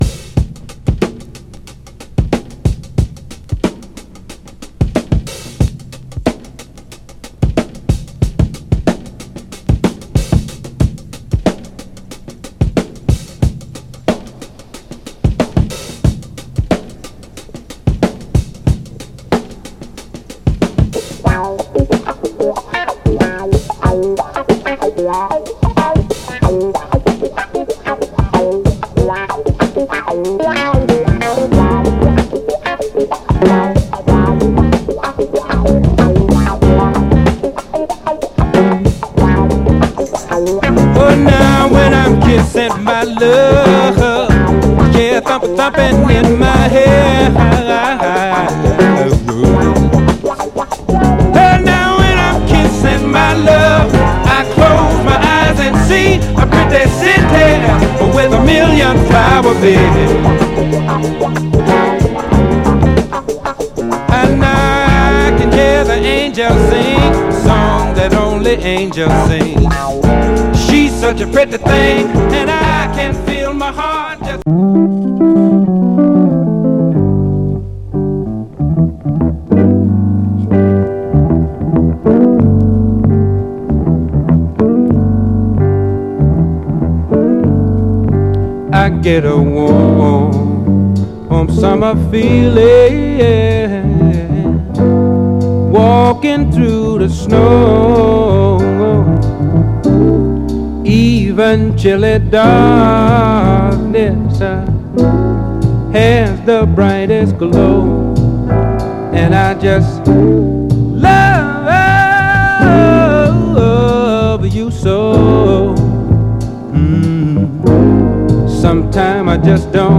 ※試聴音源は実際にお送りする商品から録音したものです※